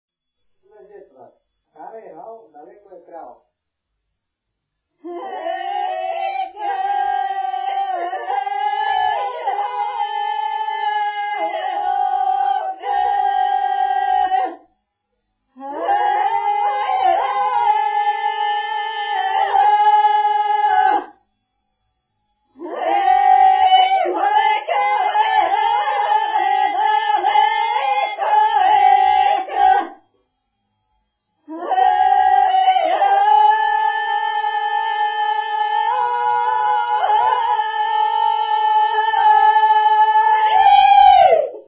музикална класификация Песен
форма Двуредична с рефрен (R)
размер Безмензурна
фактура Двугласна
начин на изпълнение Група (без отпяване)
функционална класификация Трудови (навън)
битова функция На жетва
фолклорна област Средна Западна България
начин на записване Магнетофонна лента
артефакти/типология Автентична